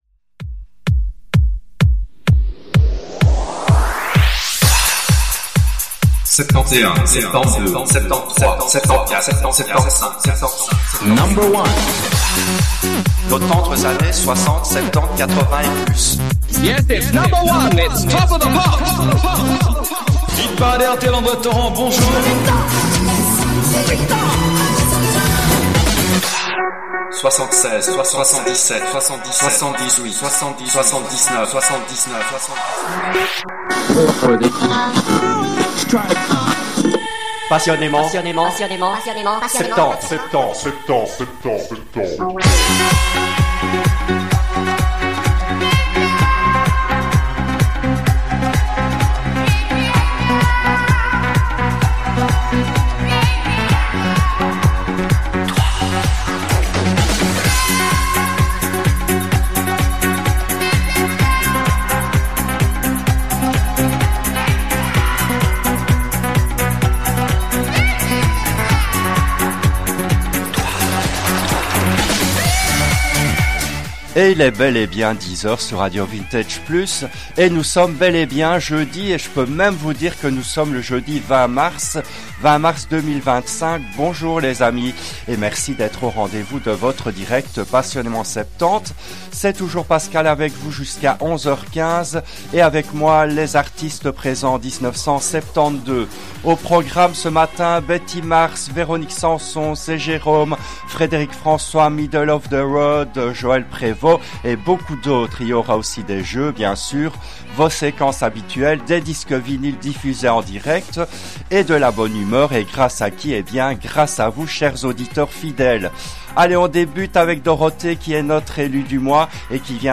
L’émission a été diffusée en direct le jeudi 20 mars 2025 à 10h depuis les studios belges de RADIO RV+.